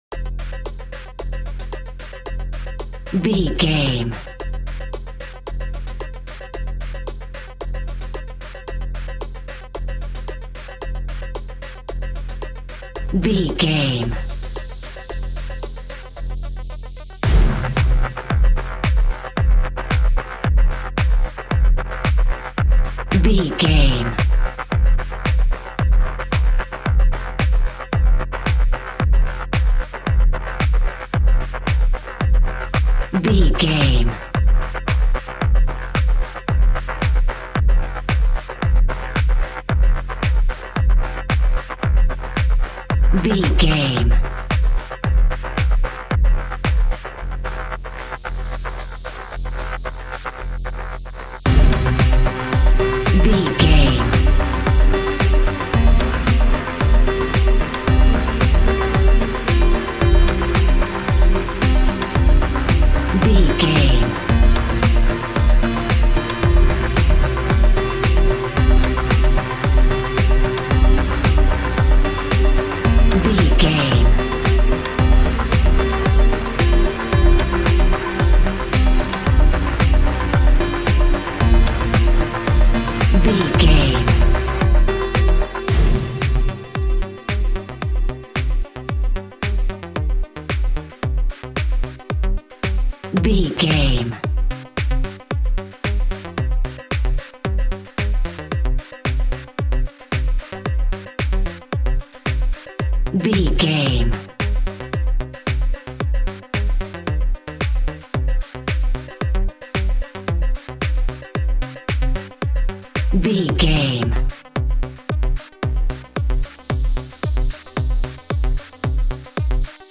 Modern Chart Pop Electronic Full.
Aeolian/Minor
groovy
uplifting
driving
energetic
repetitive
synthesiser
drum machine
house
electro dance
techno
trance
synth leads
synth bass
upbeat